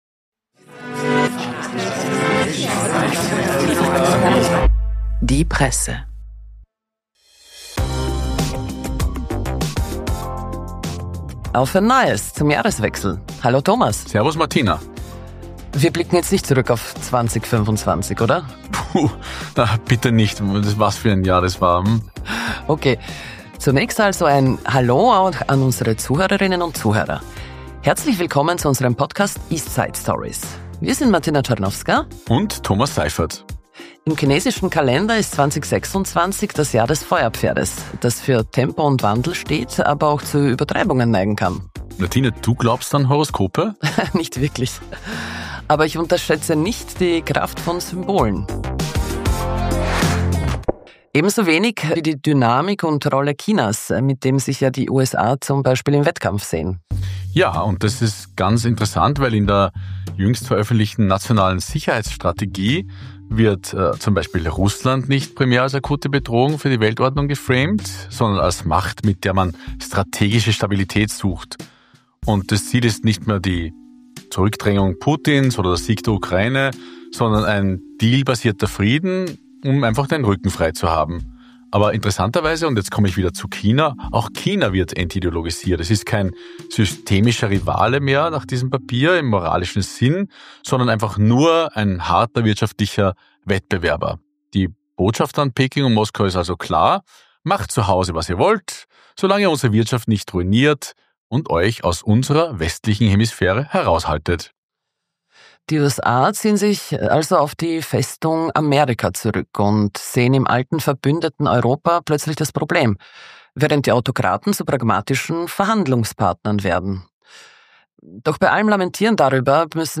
Die albanisch-britische Politikphilosophin Lea Ypi im Gespräch über die EU-Erweiterung als symbolisches Projekt, die Krise der Globalisierung und warum Europa eine transnationale Alternative zum Marktkapitalismus braucht.